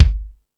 Lotsa Kicks(16).wav